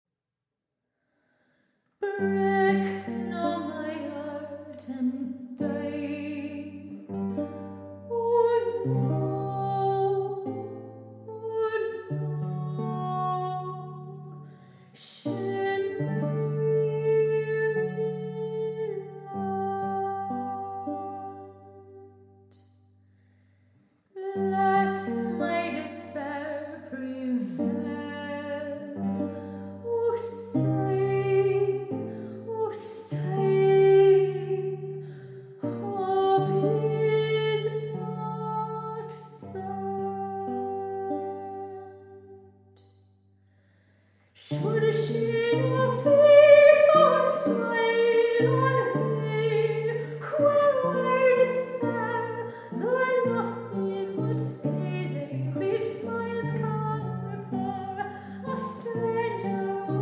English, Scottish, and Irish lute songs